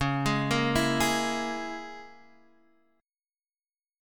C#m6 chord